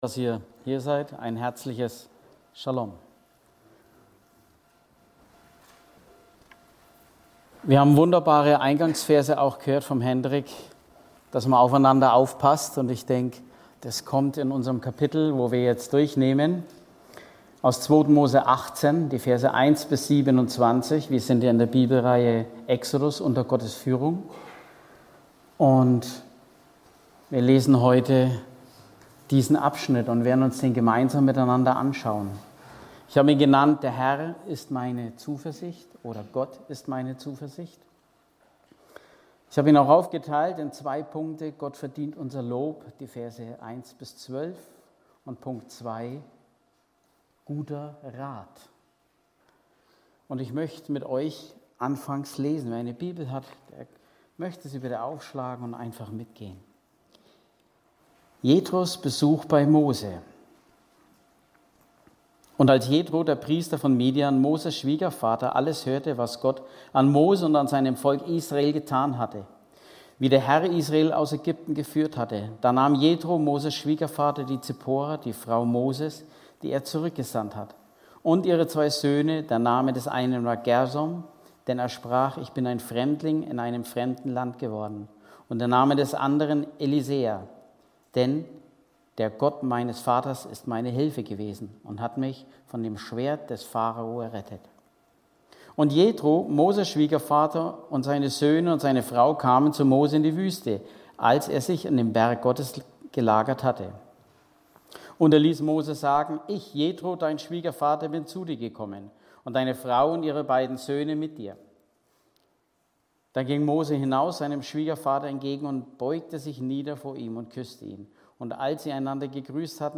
Heute predigte